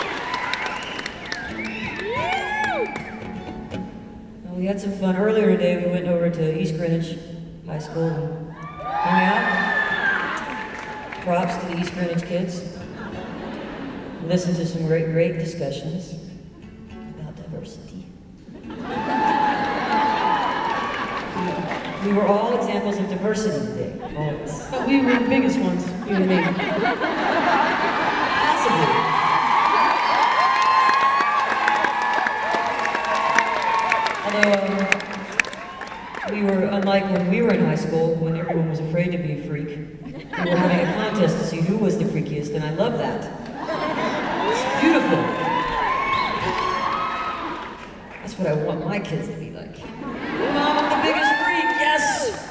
04. talking with the crowd (0:52)